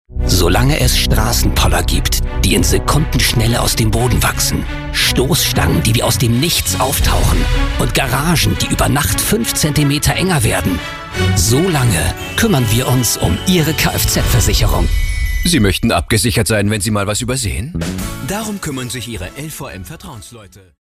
markant, plakativ
Mittel plus (35-65)
Norddeutsch
Eigene Sprecherkabine
Commercial (Werbung)